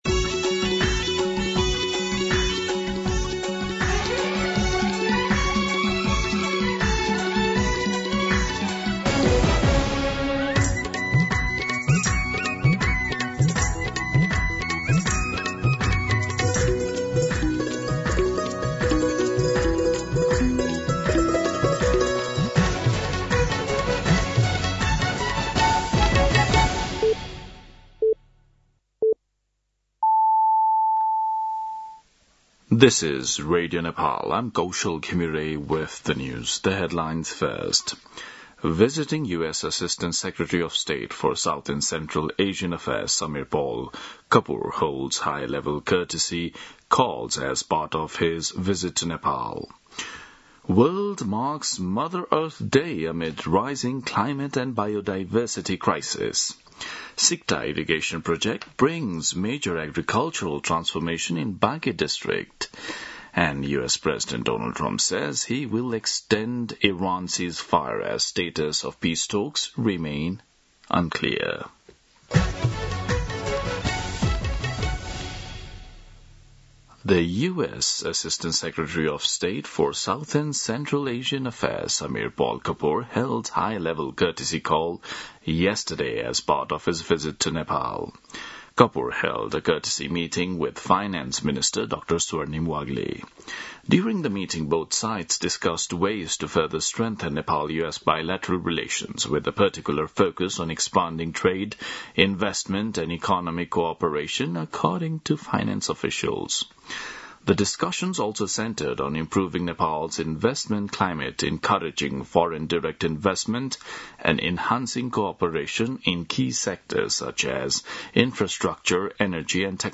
दिउँसो २ बजेको अङ्ग्रेजी समाचार : ९ वैशाख , २०८३
2pm-English-News-09.mp3